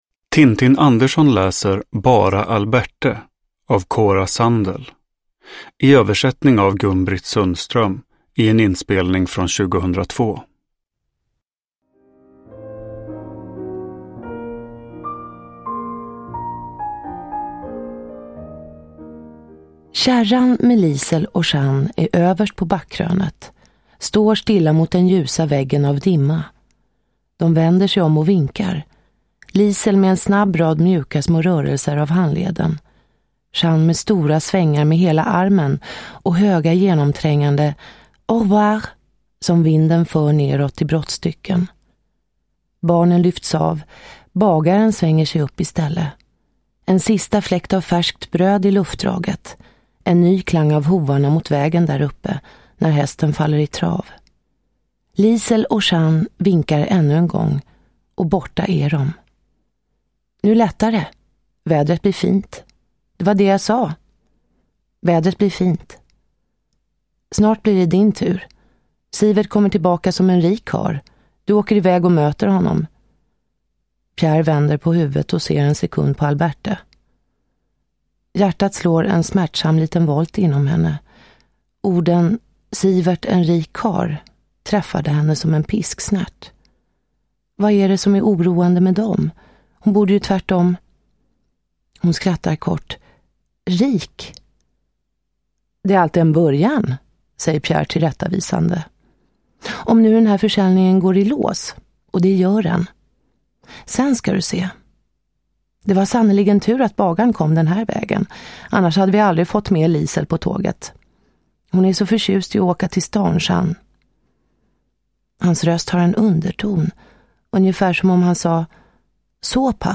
Bara Alberte – Ljudbok – Laddas ner